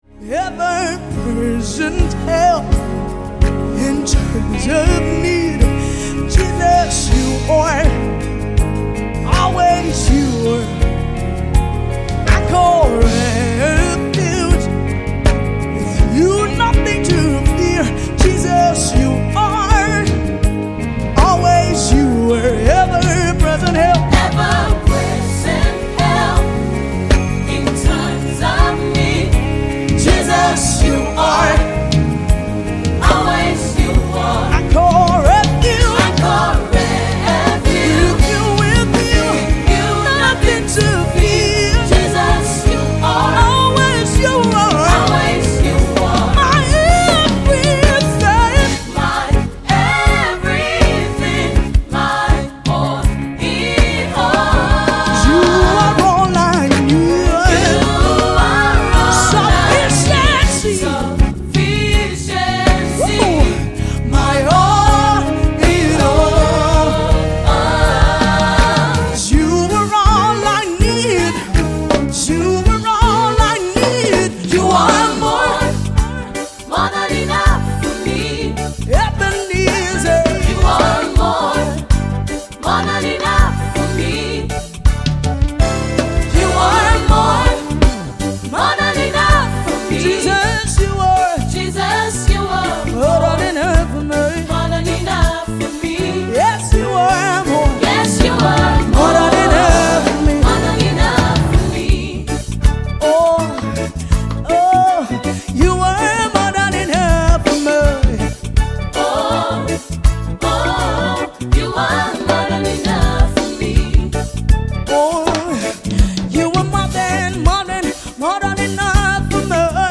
Popular Nigerian gospel singer and songwriter